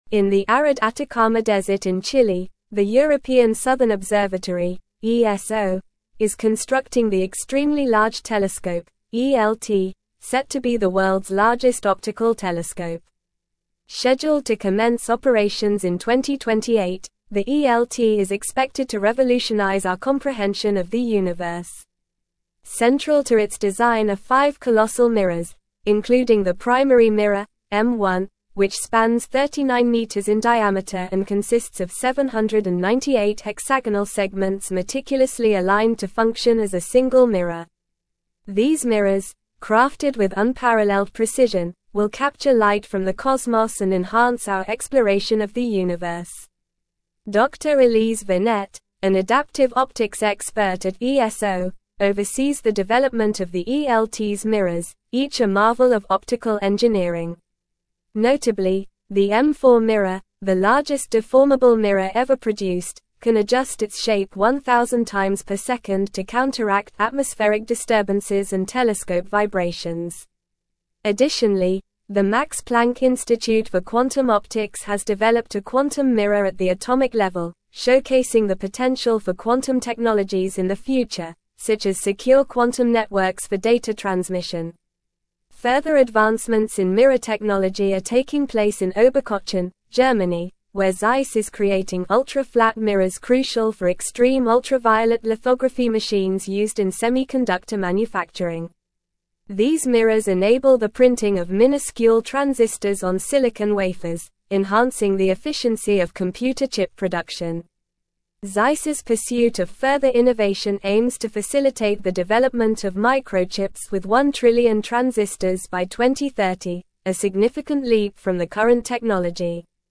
Normal
English-Newsroom-Advanced-NORMAL-Reading-Advancing-Technology-Through-Precision-The-Power-of-Mirrors.mp3